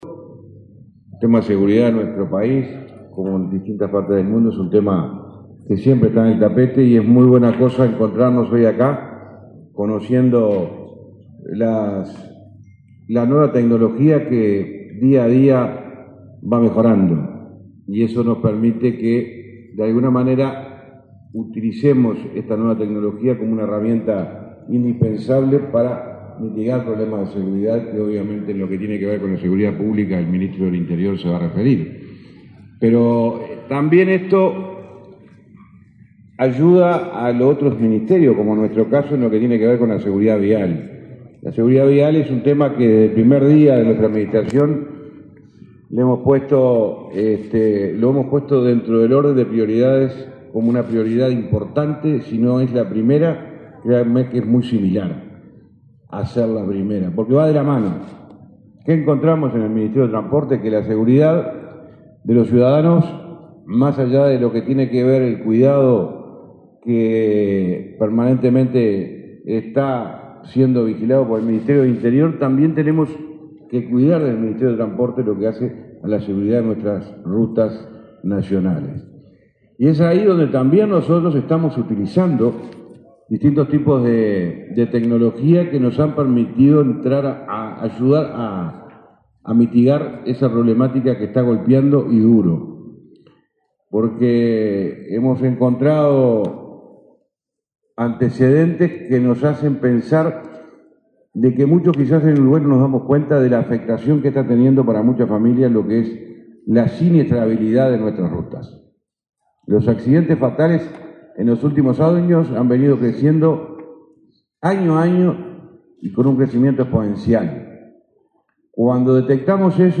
Disertación del ministro de Transporte, José Luis Falero
El ministro de Transporte, José Luis Falero, disertó, en la Expo Segura, que se realiza desde este viernes 4 y hasta el domingo 6 en el Laboratorio